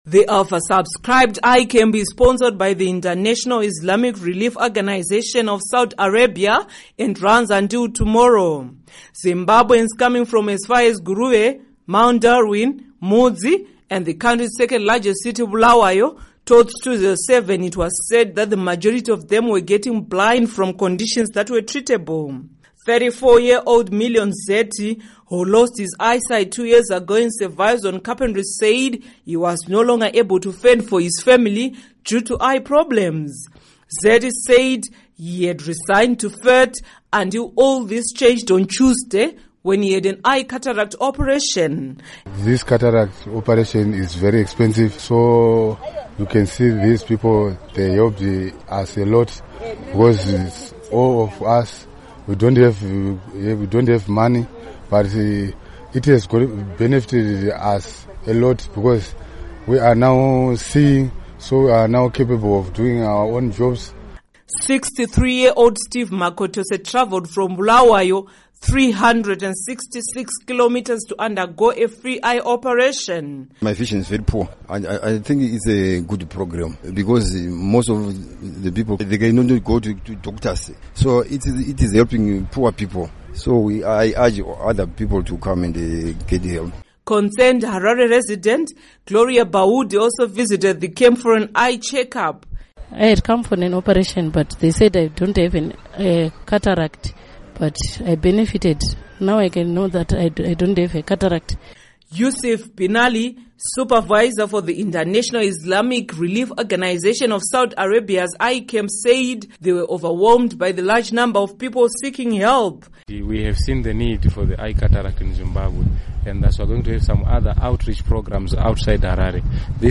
Report on Eye Operations